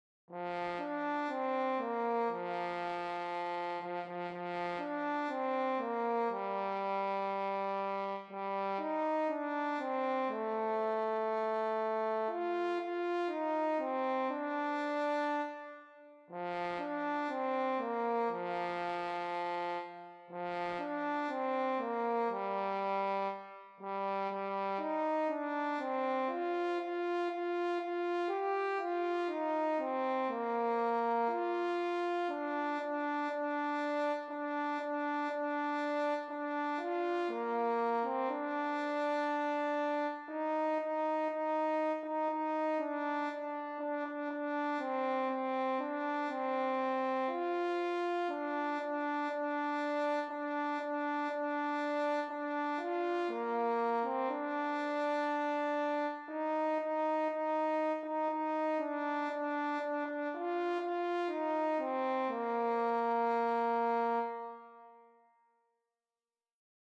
für Horn in F solo